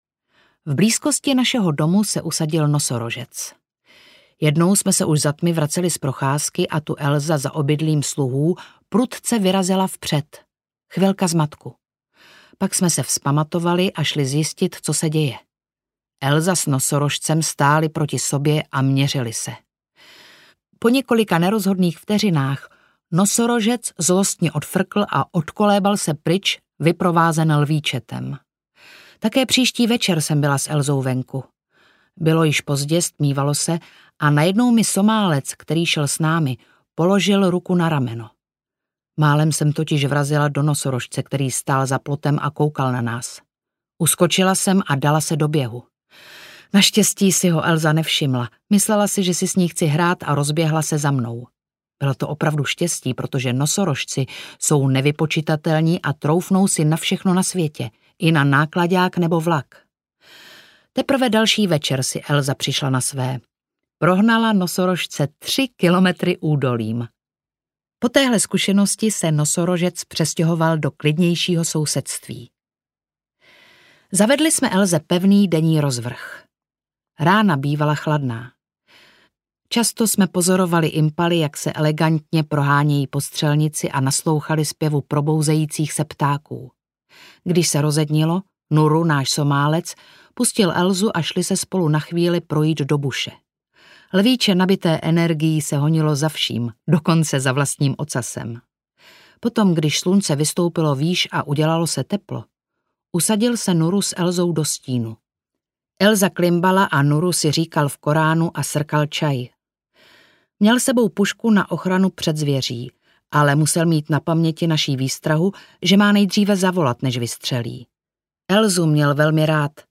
Příběh lvice Elsy audiokniha
Ukázka z knihy